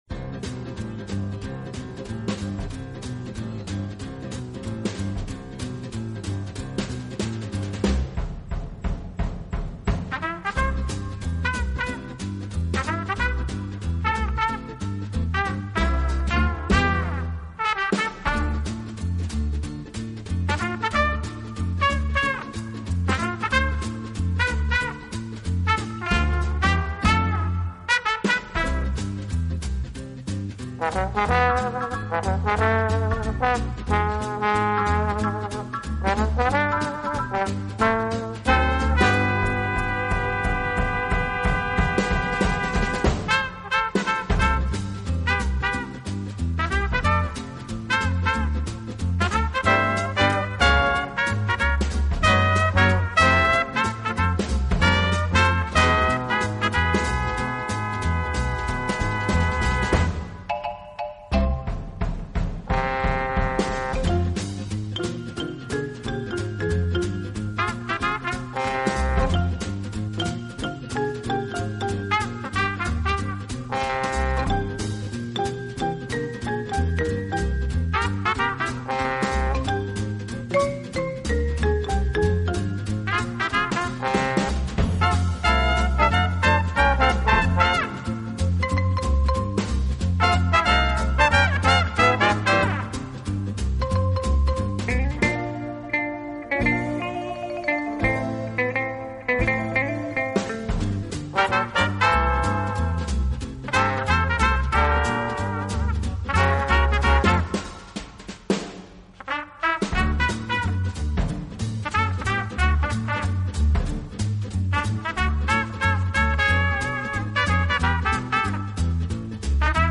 以擅长演奏拉丁美洲音乐而著称。演奏轻柔优美，特別是打击
乐器的演奏，具有拉美音乐独特的韵味。